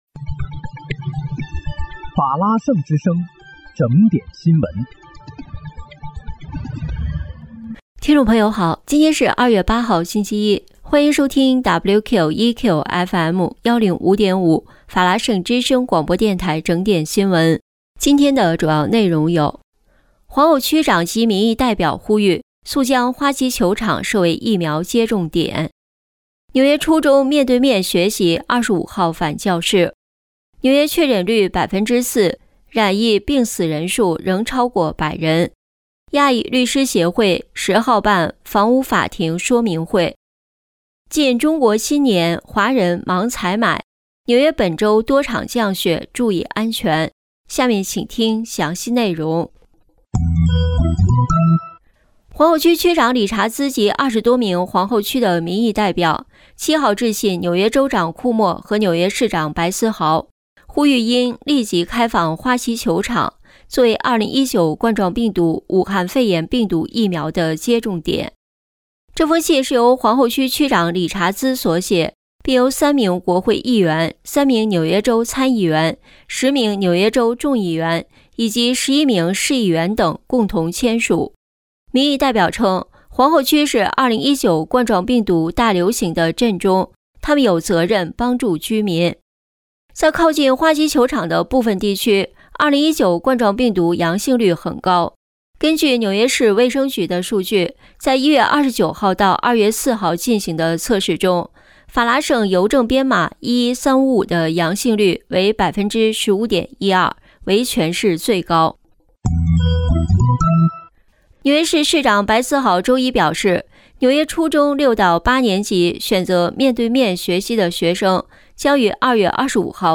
2月8日(星期一）纽约整点新闻